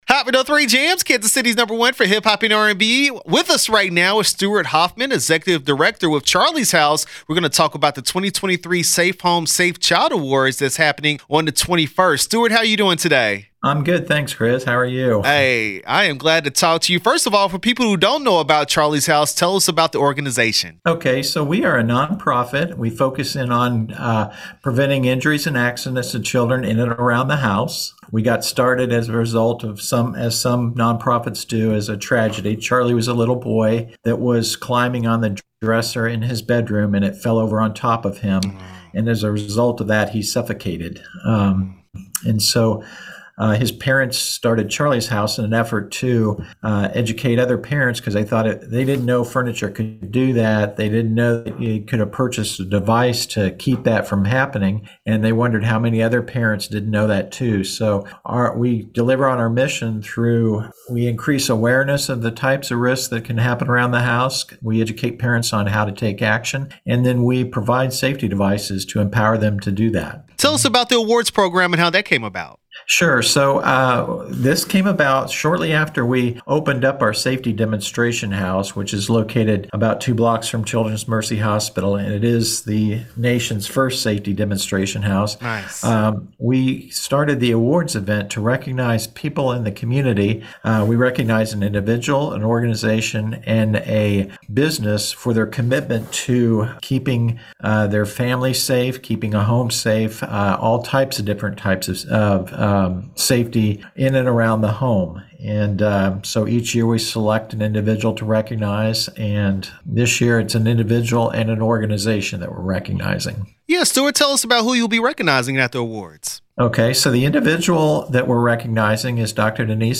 Charlie’s House Safe Home Child Awards interview 9/19/23